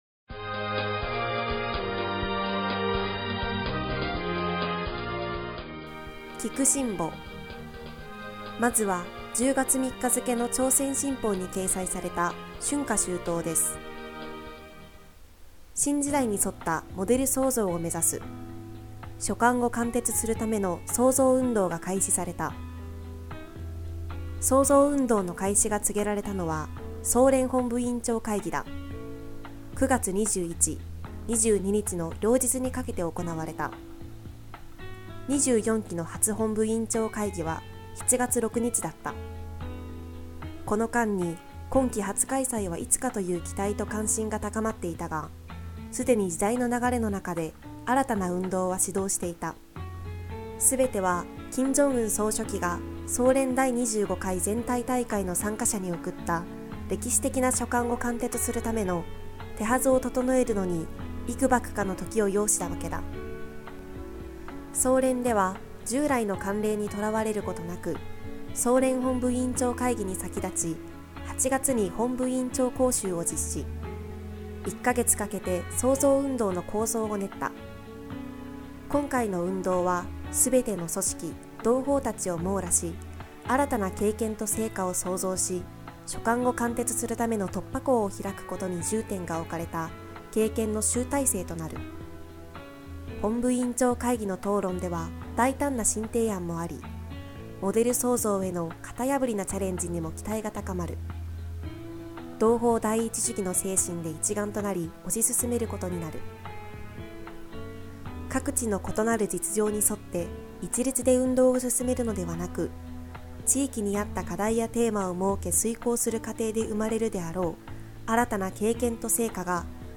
「聴くシンボ」は、朝鮮新報電子版 DIGITAL SINBOのニュースを音声でお聞きいただけます。